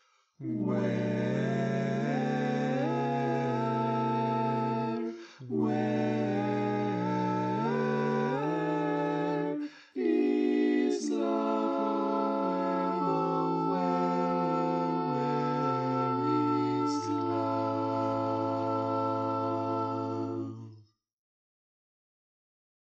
Key written in: A Major
How many parts: 4
Type: Barbershop